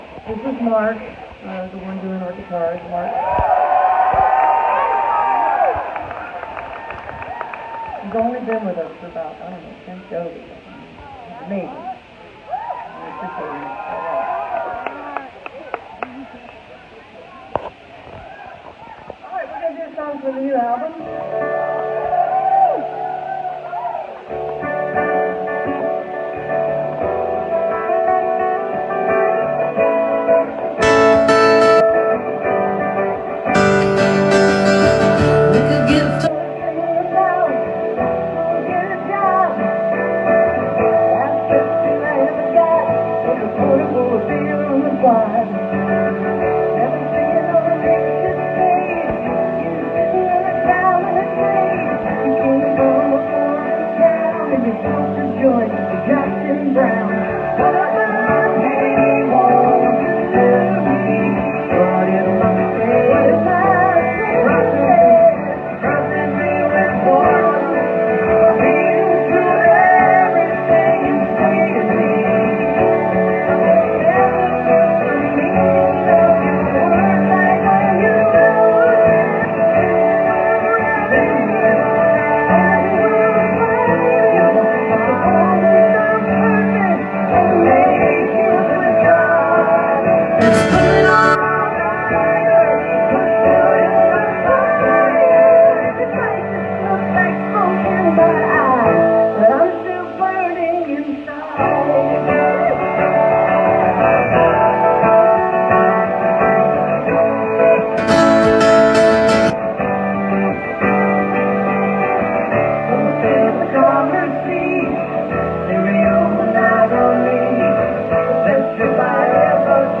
(captured from a facebook live stream)